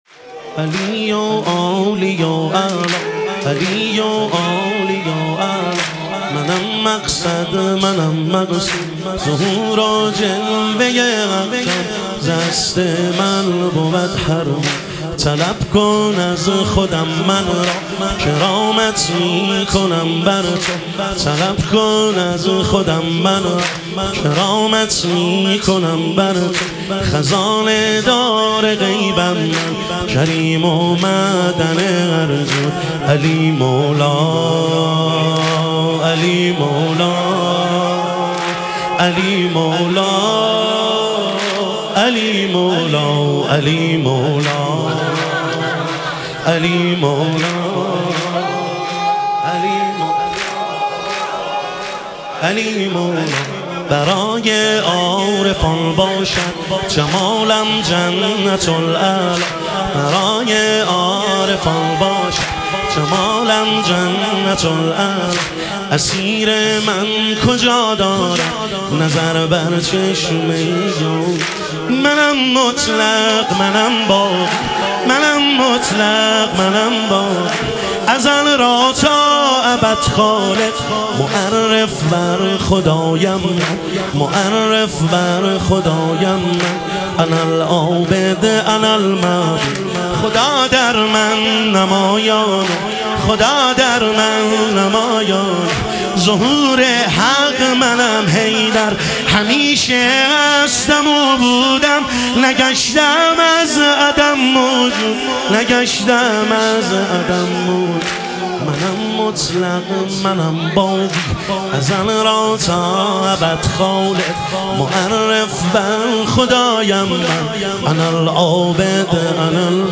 اربعین امیرالمومنین علیه السلام - واحد